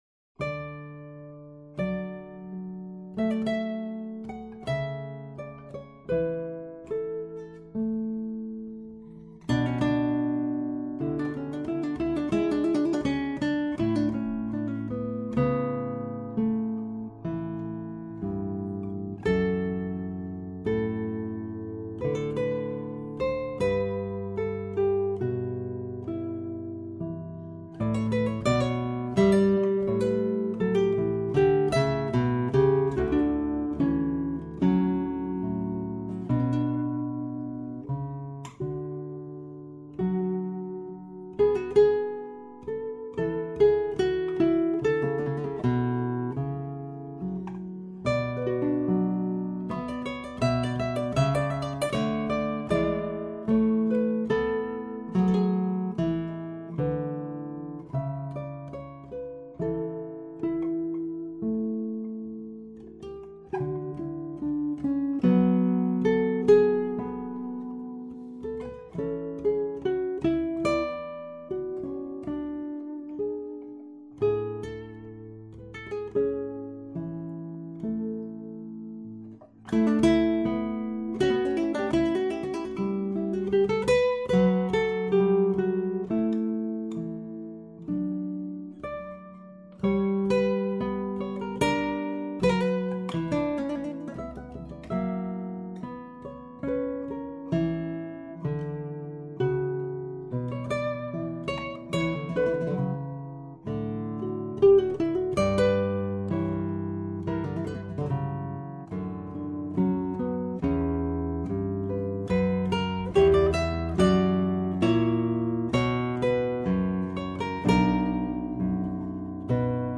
音樂類型：古典音樂
關于這張吉他版的專輯
採用2－4把吉他分別演奏各個聲部，然後混音，其中兩把bass吉他是專為這張專輯特製的。